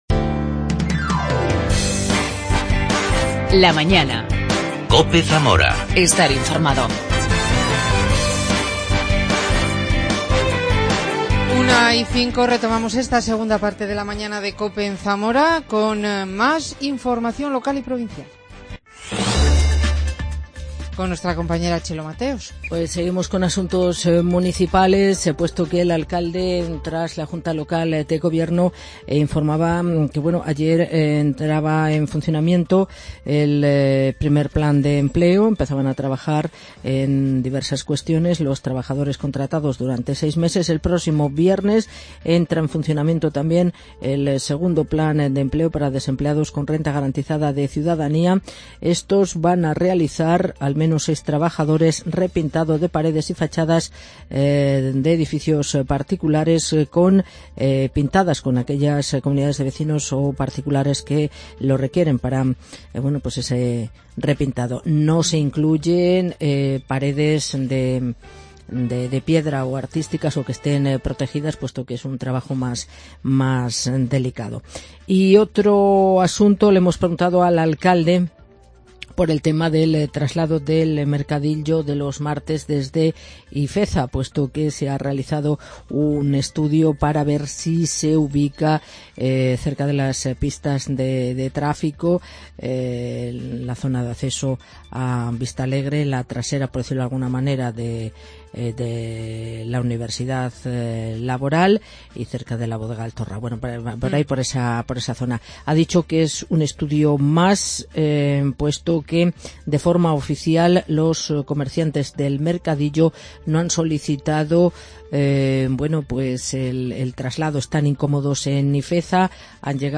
Entrevista al escritor y bodeguero